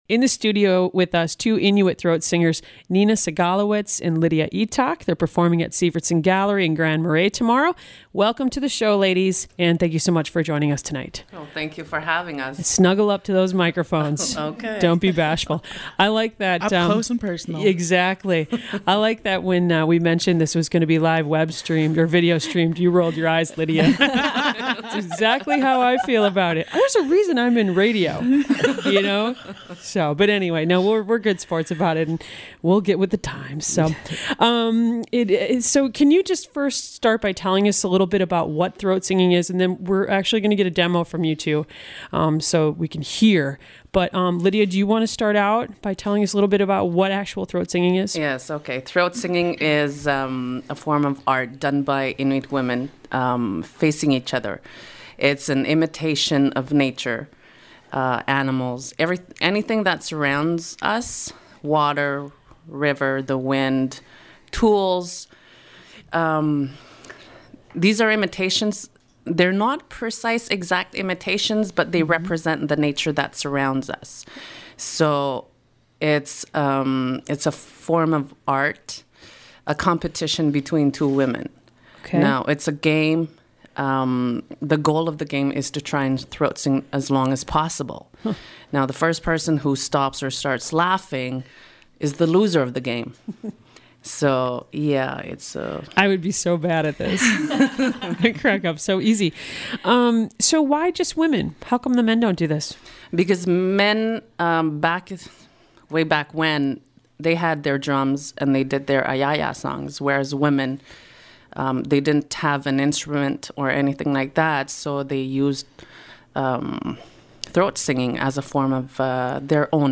Inuit throat singers
Take a listen--you don't hear this amazing & unusual art form every day. Program: The Roadhouse